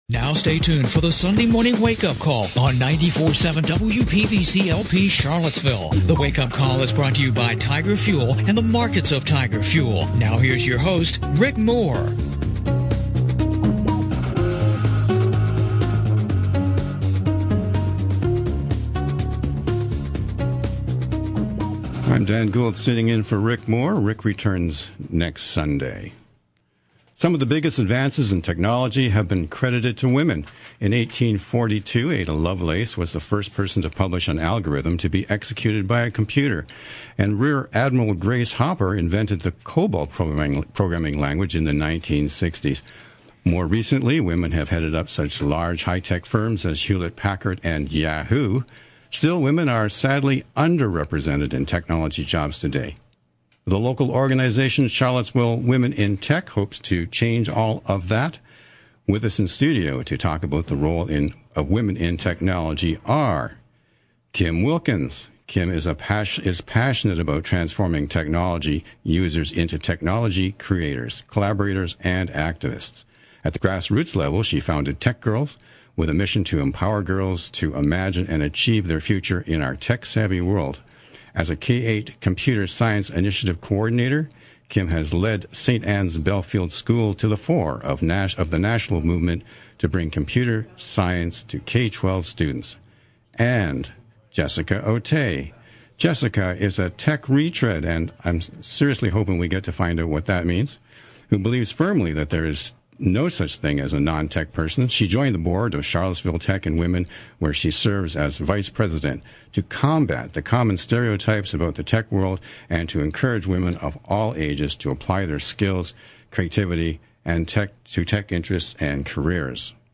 The interview was recorded on location at the SPCA shelter on Berkmar Drive in Albemarle County.